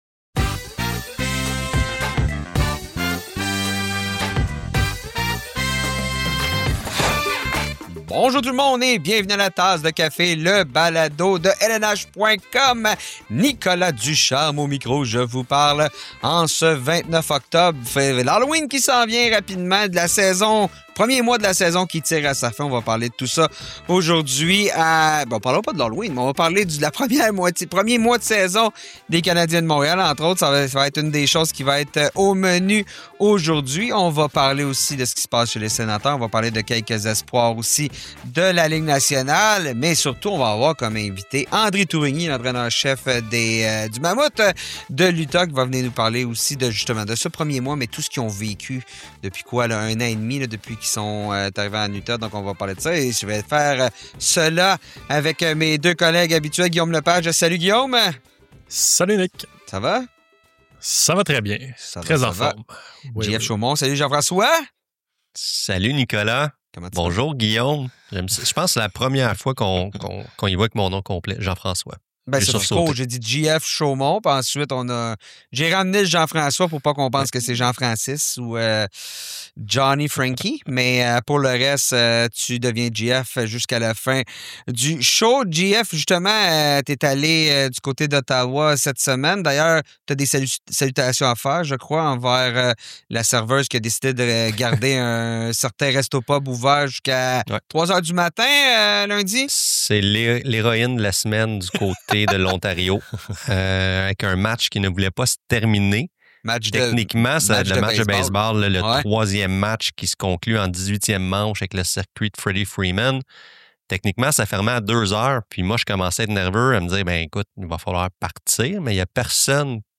C’est ce que nous explique aujourd’hui l’entraîneur-chef de l’équipe André Tourigny.